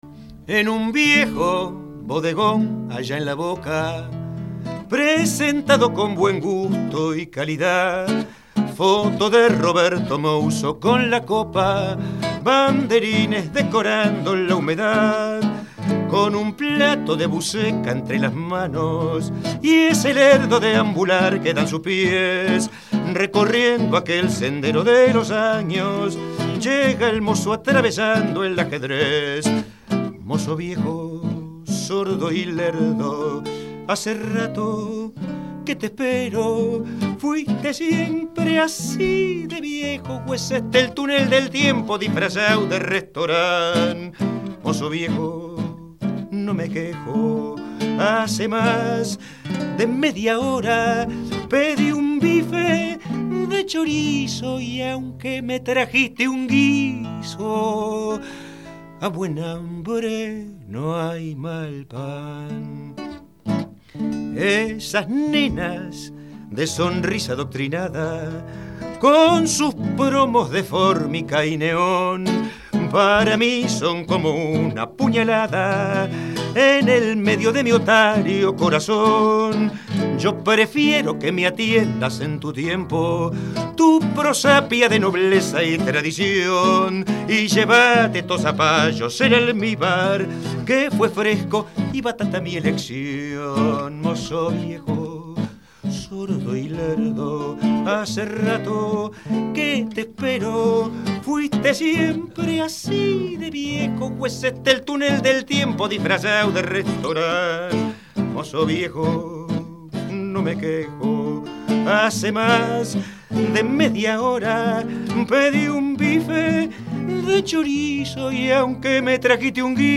cantó tres tangazos…